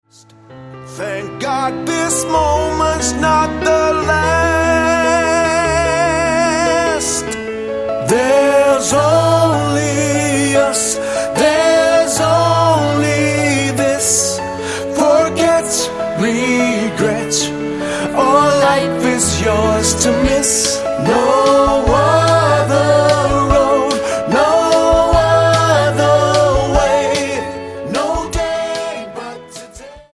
Tonart:A Multifile (kein Sofortdownload.
Die besten Playbacks Instrumentals und Karaoke Versionen .